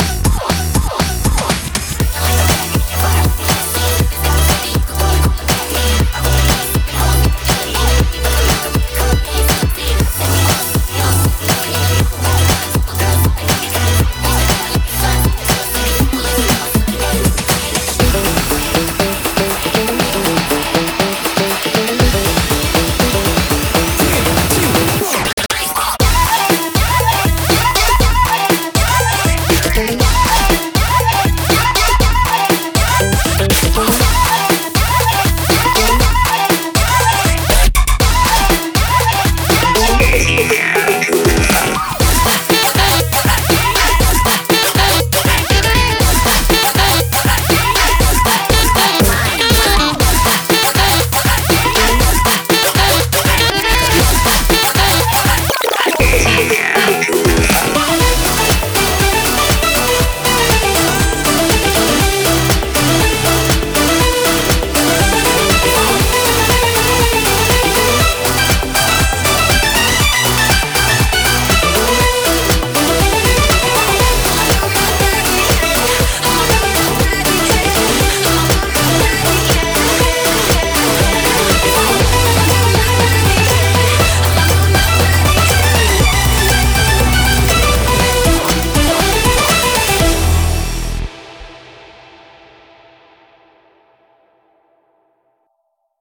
BPM120